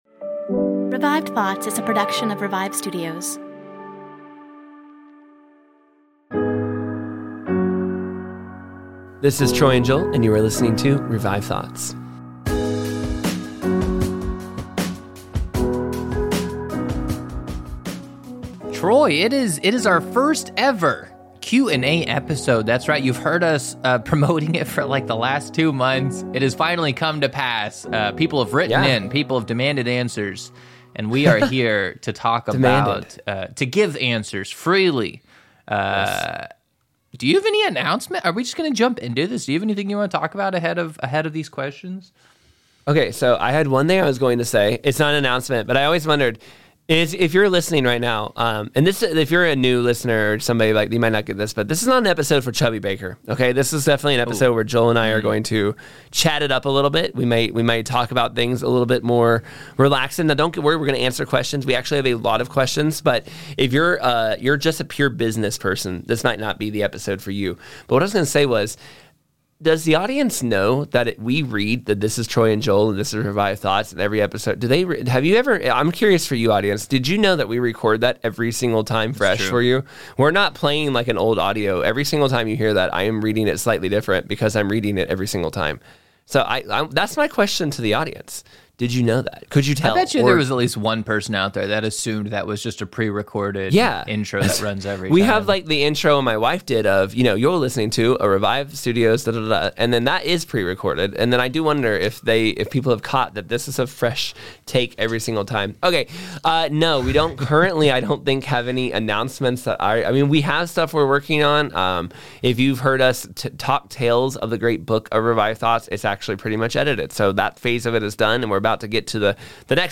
We are bringing history's greatest sermons back to life!